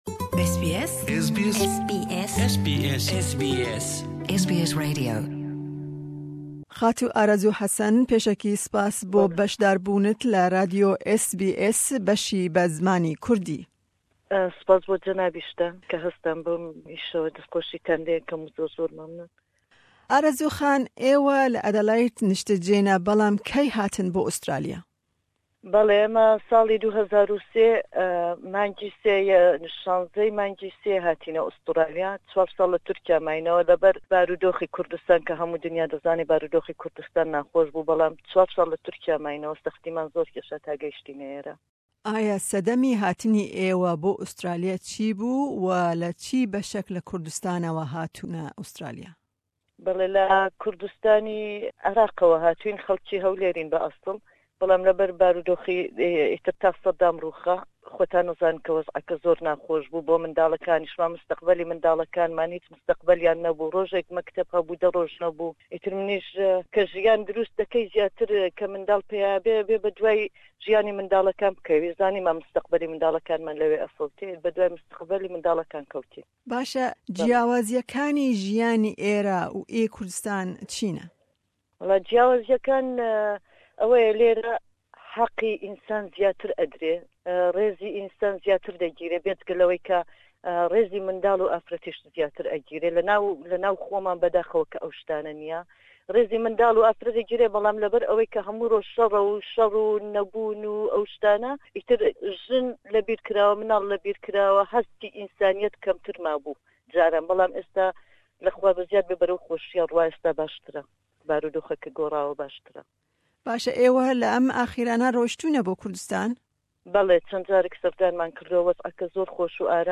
Me derbarî meraqa wê ya di dirûna cilên Kurdî de hevpeyvînek bi wê re pêk anî.